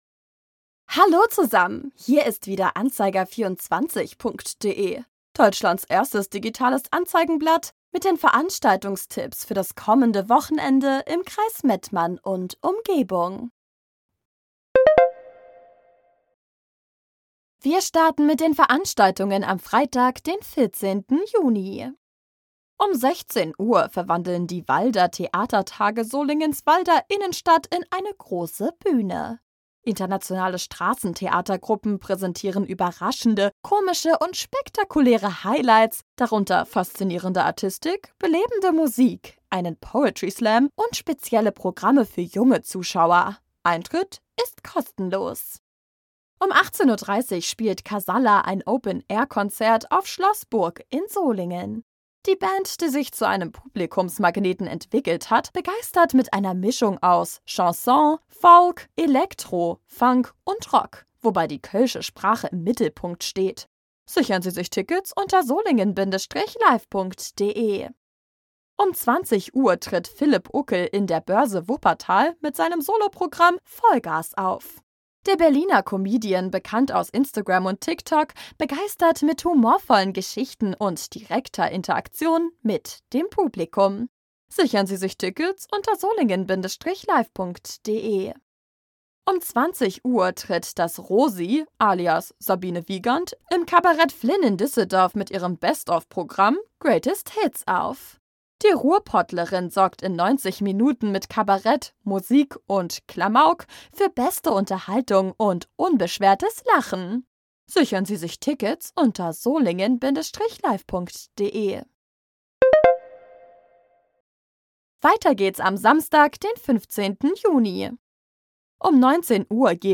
„Musik“